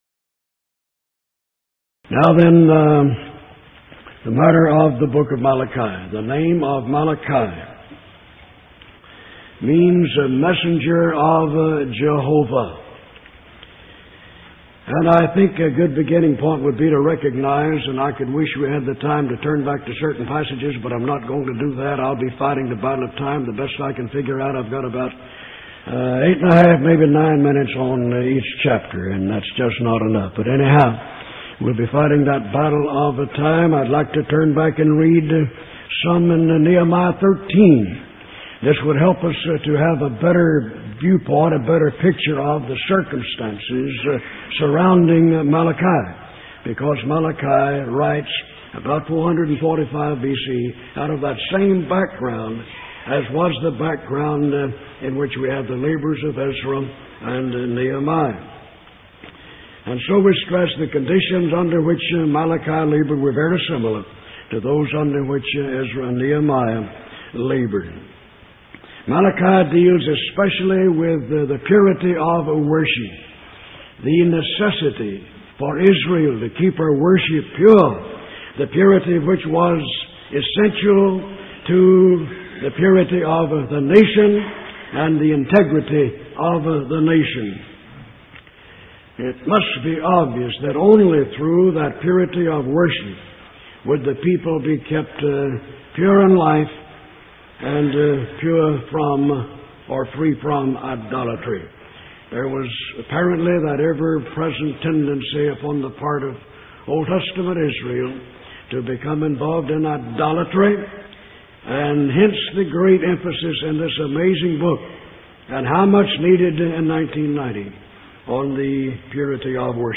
Event: 1990 Power Lectures
lecture